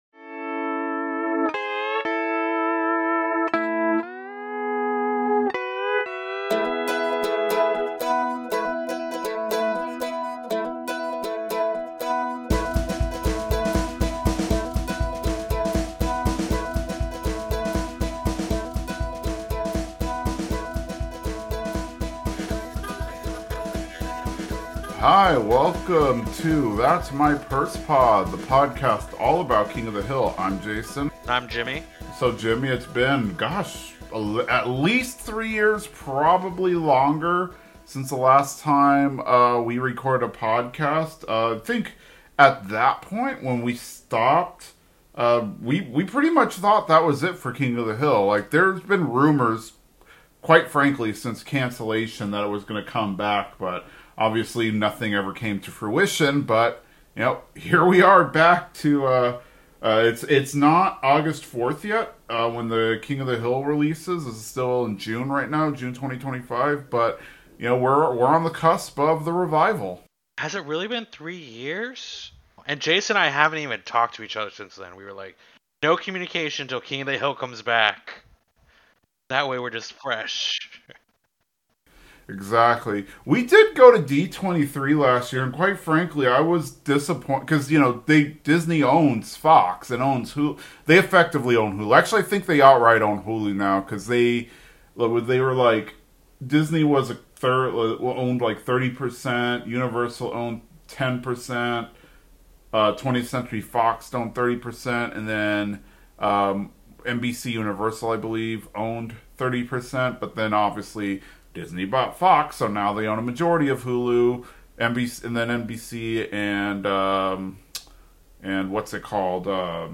Apologies for audio quality, we are testing some new software and still have a lot to learn. But we are back in the saddle to talk about King of the Hill.